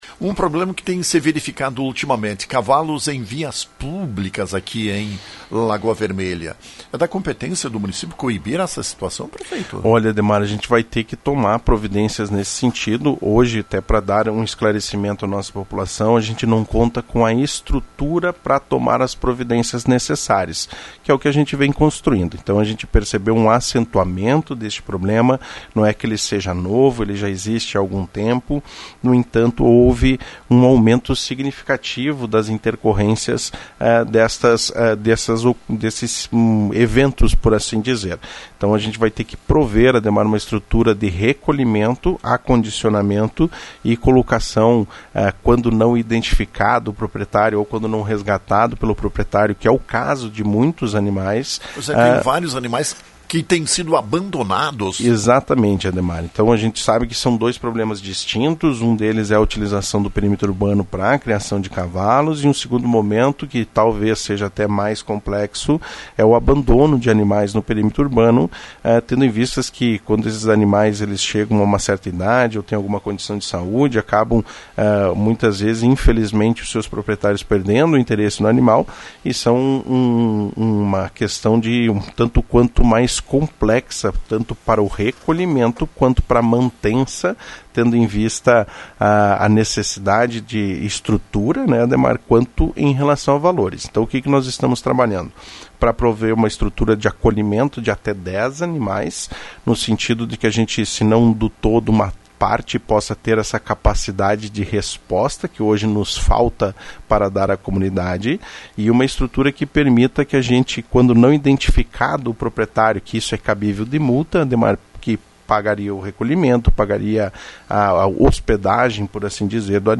Prefeito Gustavo Bonotto abordou esse assunto em entrevista à Rádio Lagoa FM na manhã desta segunda-feira.